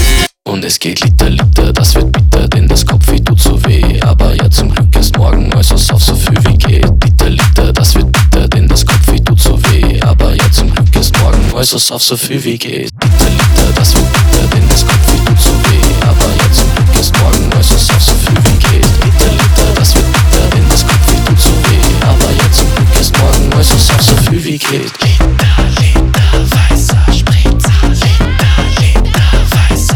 Pop German Pop
Жанр: Поп музыка